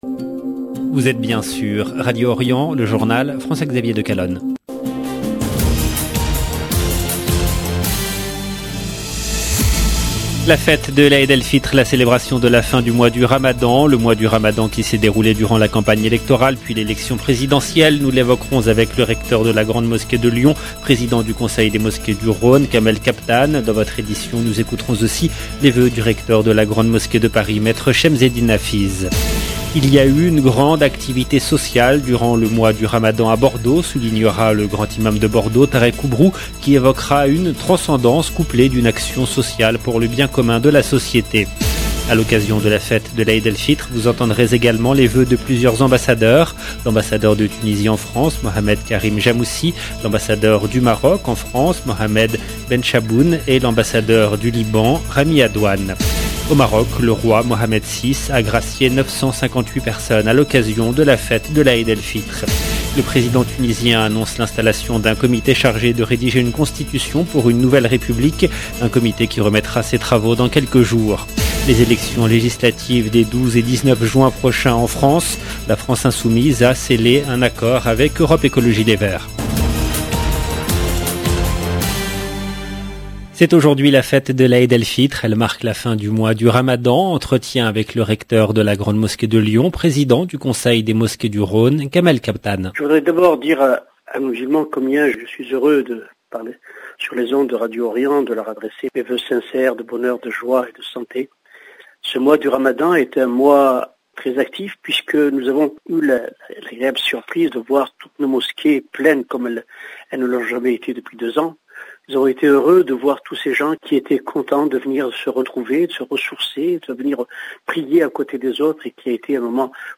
À l’occasion de la fête de l’Aïd El Fitr, vous entendrez également les vœux de plusieurs ambassadeurs.